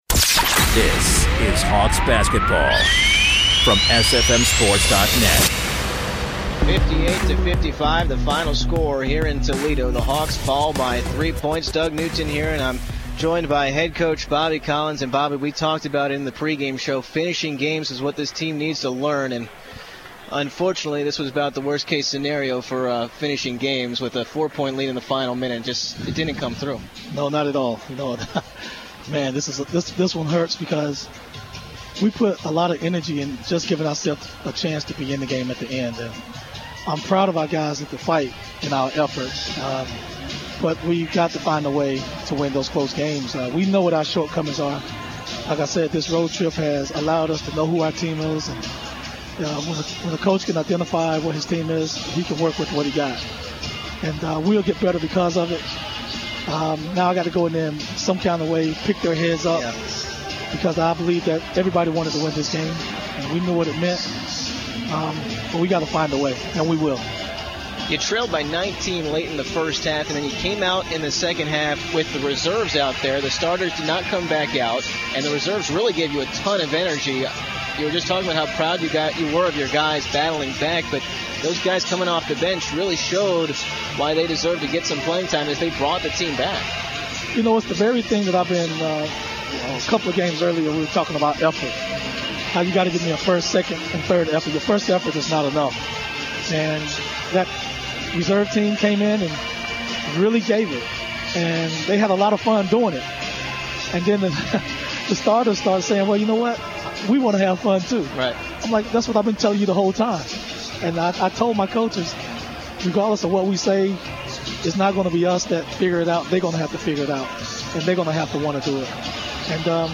11/25/14: UMES Men's Basketball Post Game Show